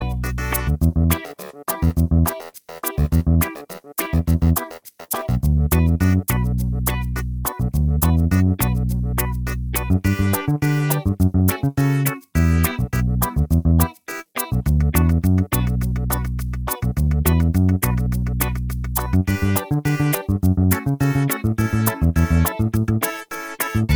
Minus Lead Guitar And Drums Reggae 3:53 Buy £1.50